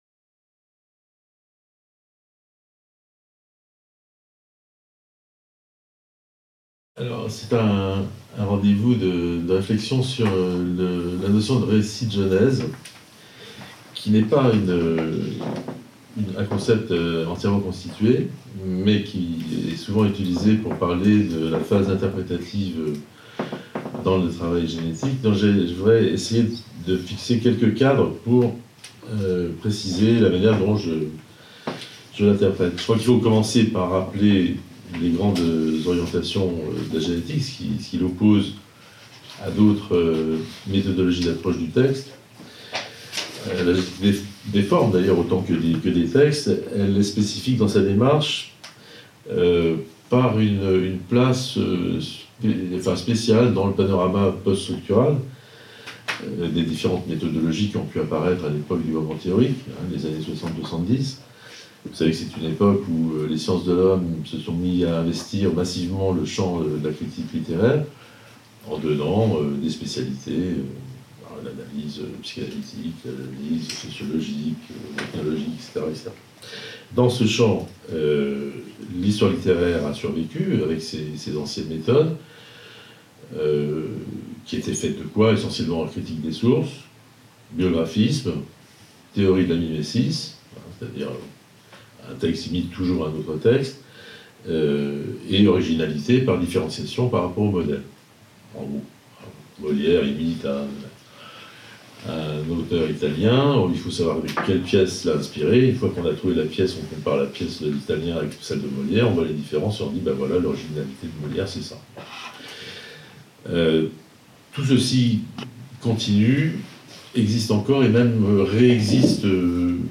Pensée sur le format d'une discussion entre plusieurs participants